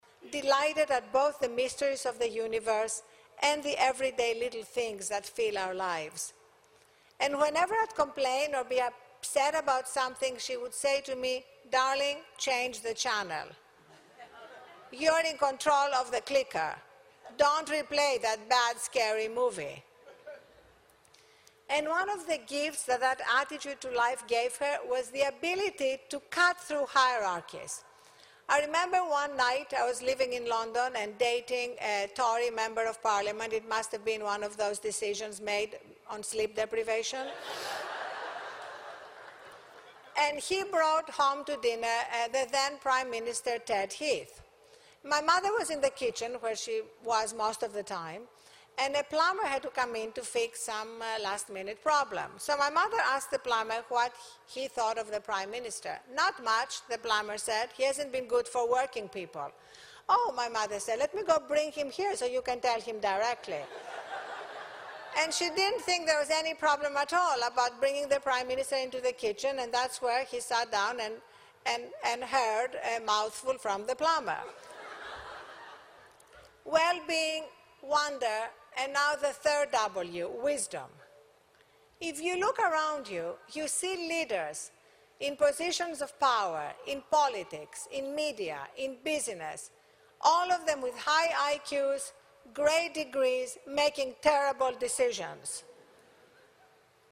公众人物毕业演讲第320期:阿丽安娜.哈芬顿2013史密斯学院(10) 听力文件下载—在线英语听力室